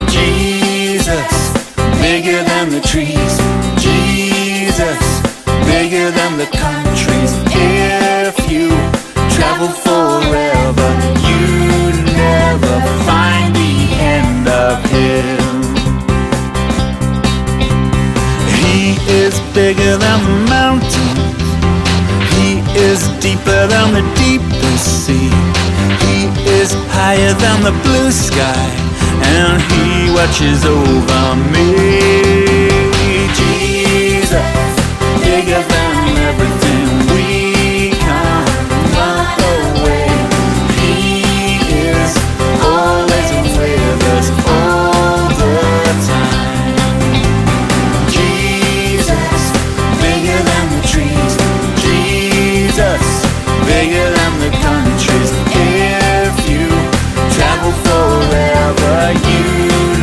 Kinderlieder